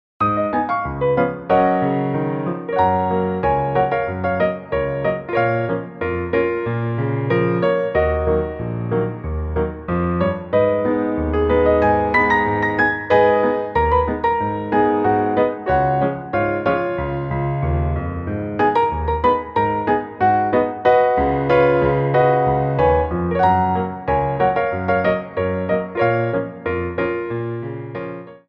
Petit Allegro 2
4/4 (16x8)